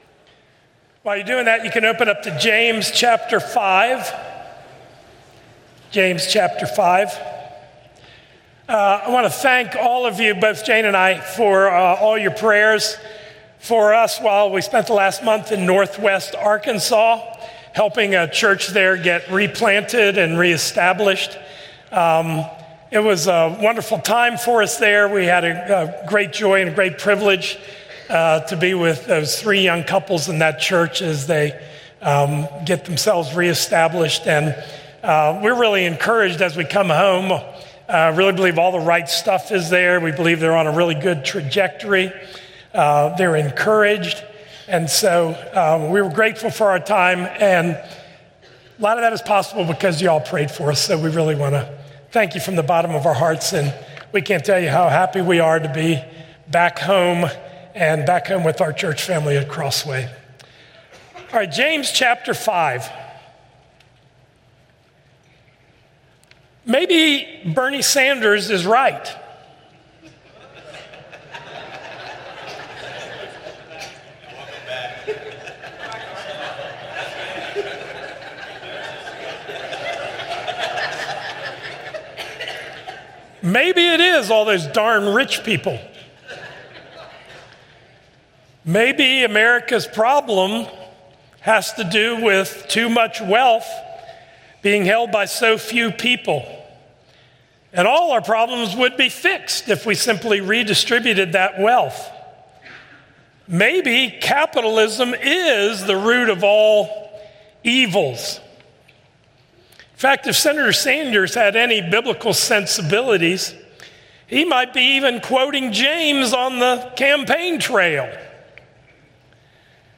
A message from the series "Faith Works."